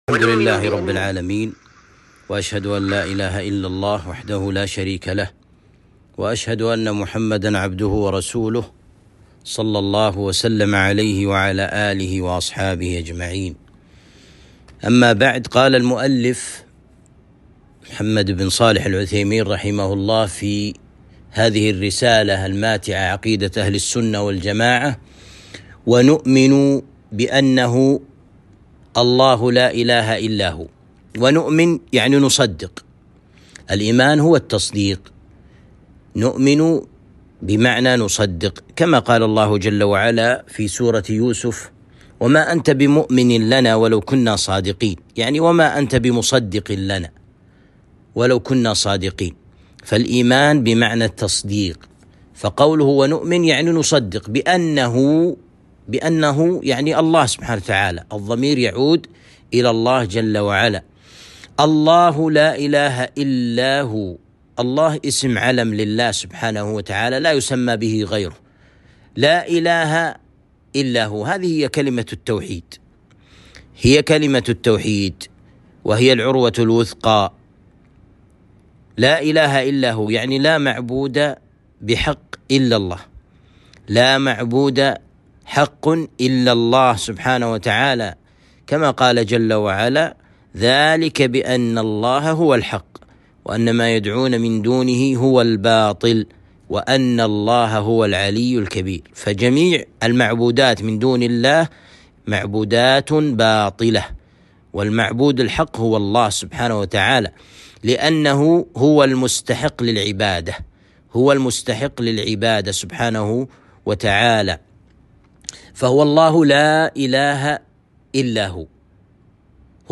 الدرس الثاني - شرح عقيدة اهل السنة والجماعة - الشيخ ابن عثيمين